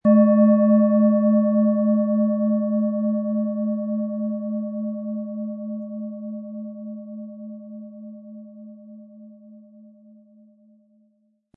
Tibetische Universal-Klangschale, Ø 11,7 cm, 100-180 Gramm, mit Klöppel
Im Preis enthalten ist ein passender Klöppel, der die Töne der Schale schön zum Schwingen bringt.
SchalenformBihar
MaterialBronze